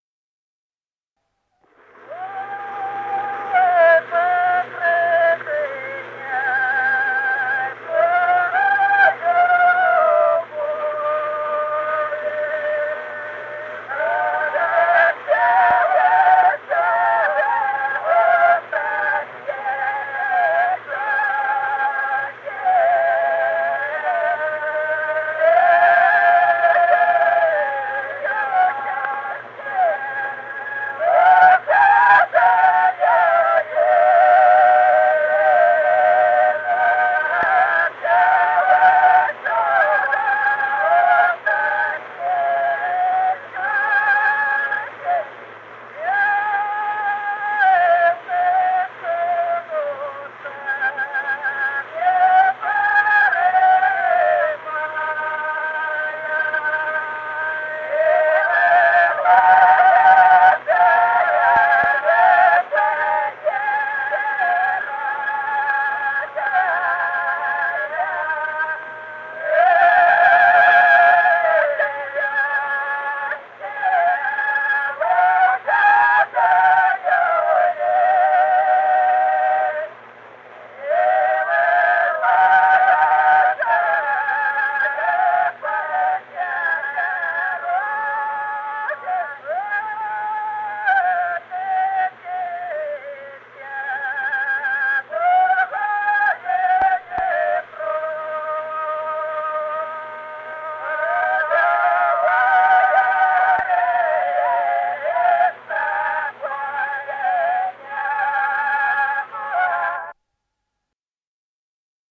Народные песни Стародубского района «Что по прежней по любови», карагодная.
1951 г., с. Остроглядово.